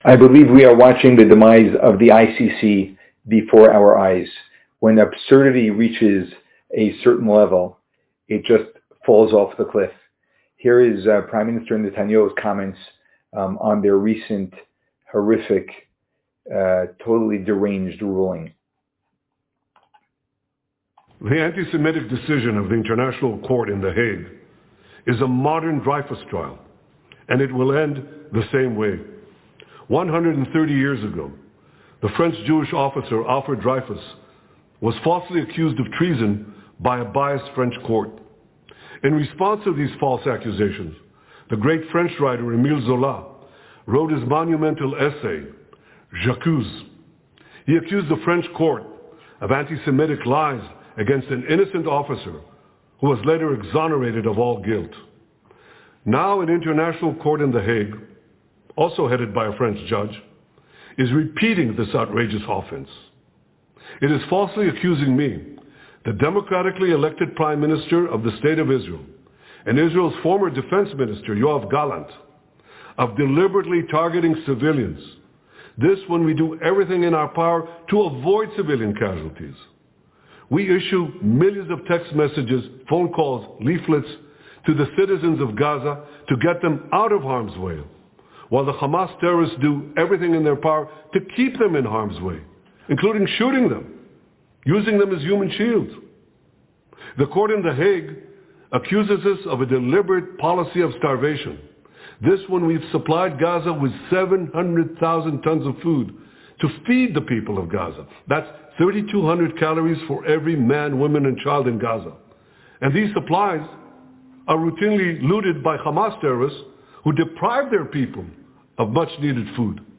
Rabbi Reacts: BB Netanyahu’s Response to ICC’s Arrest Warrent on him & Yoav Gallant.